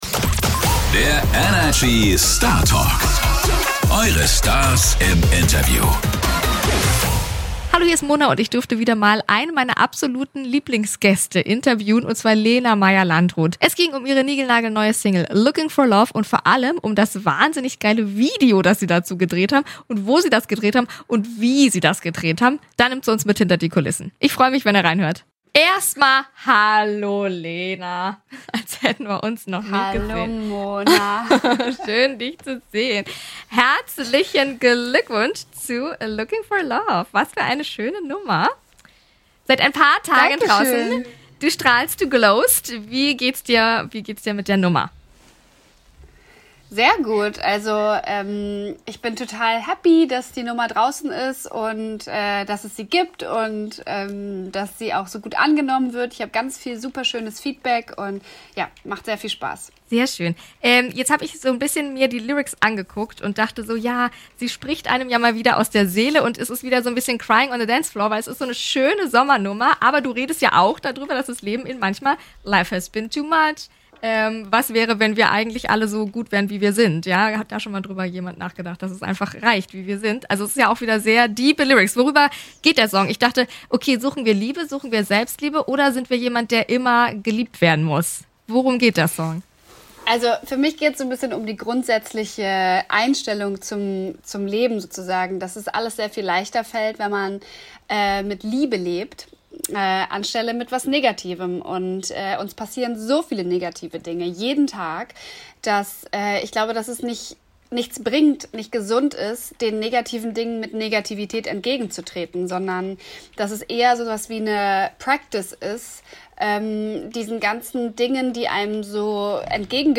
Gerade frisch den Song "Looking for Love" rausgebracht und schon ist sie bei uns im Interview.